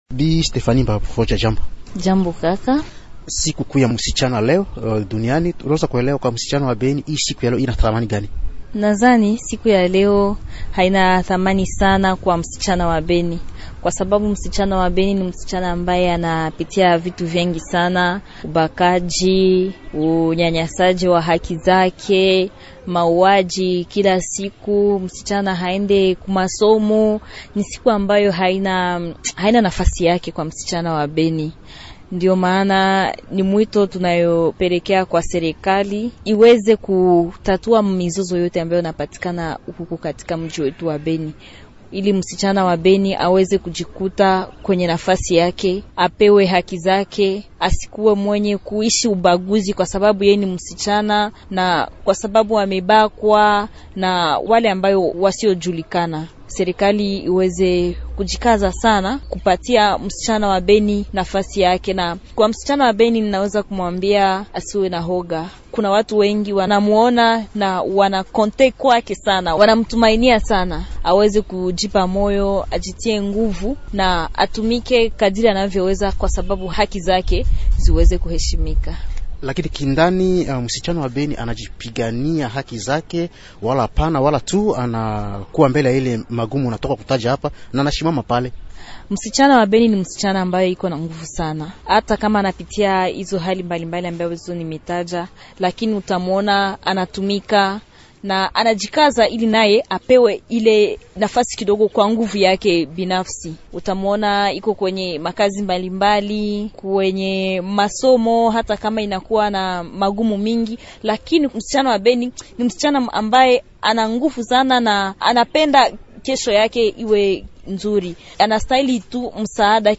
L'invité swahili, Émissions / Dégradation de l’axe routier Eringeti-Kainama, territoire de Beni